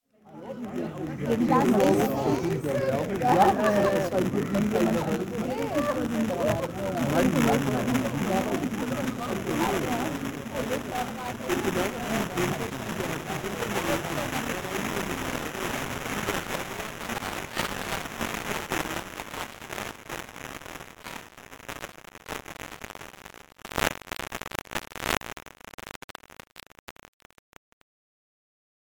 electroacoustic music
2-Kanal-Audio